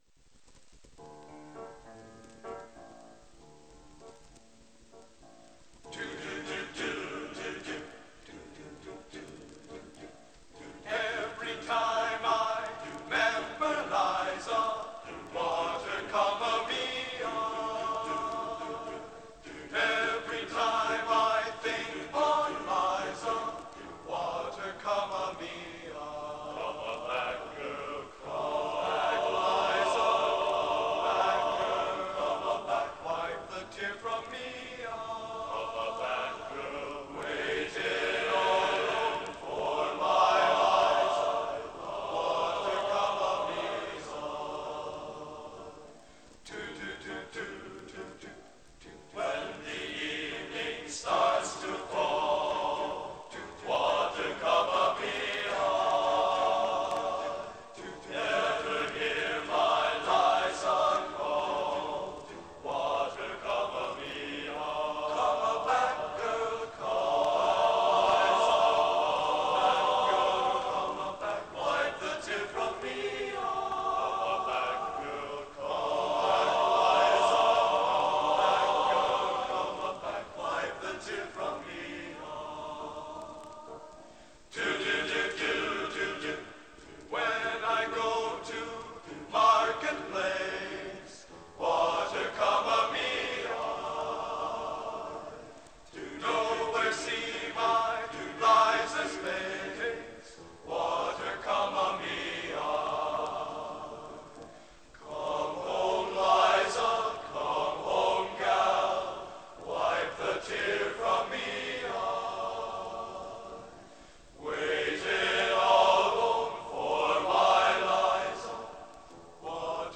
Genre: Instrumental Traditional | Type: Studio Recording